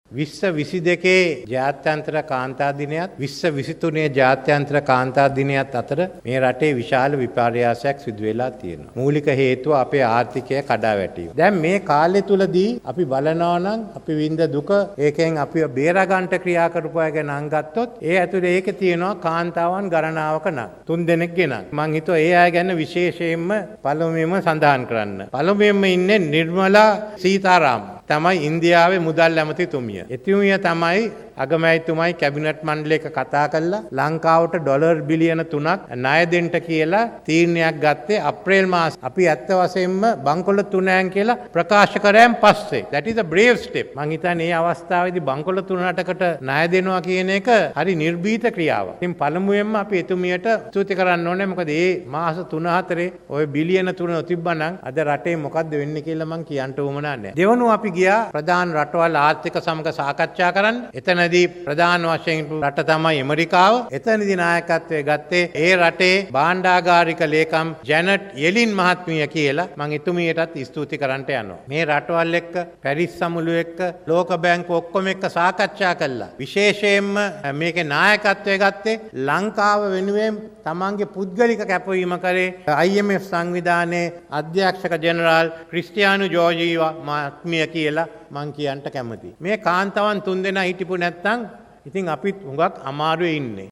ජාත්‍යන්තර කාන්තා දින ජාතික උත්සවය අමතමින් ජනපති කළ ප්‍රකාශය
මේ එහිදි අදහස් දැක්වූ ජනාධිපති රනිල් වික්‍රමසිංහ මහතා.